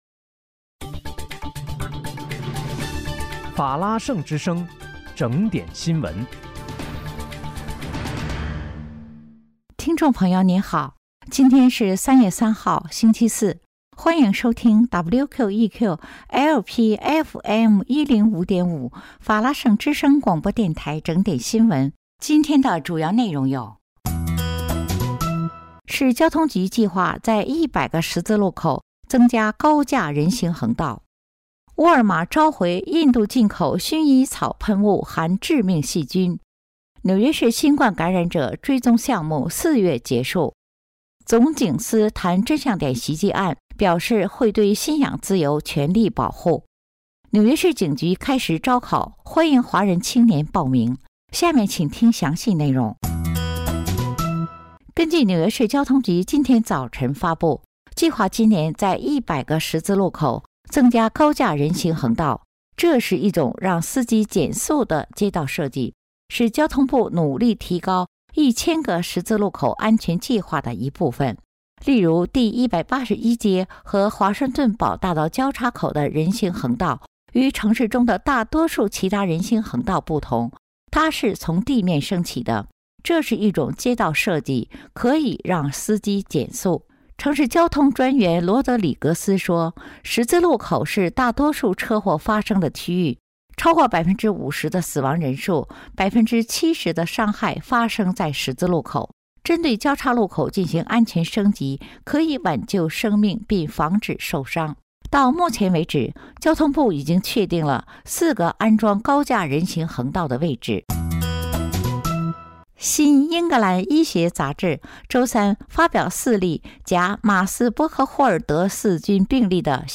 3月3日（星期四）纽约整点新闻
听众朋友您好！今天是3月3号，星期四，欢迎收听WQEQ-LP FM105.5法拉盛之声广播电台整点新闻。